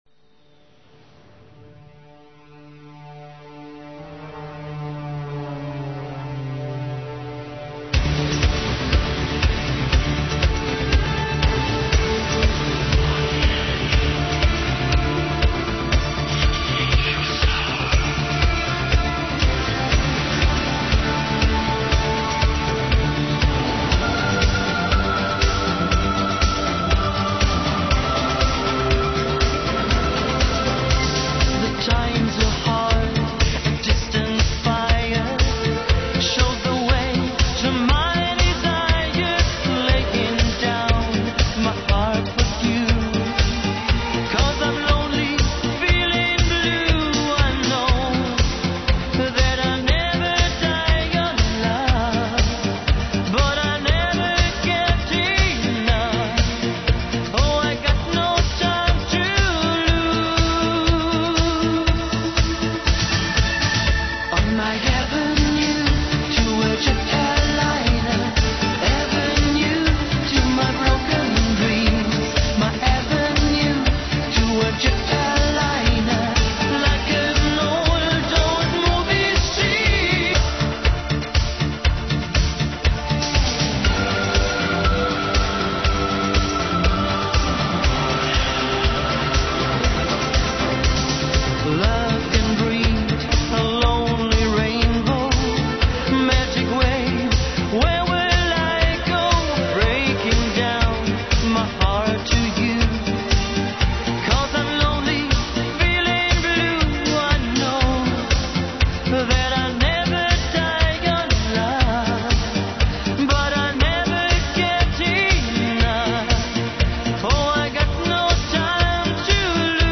80's pop at its best.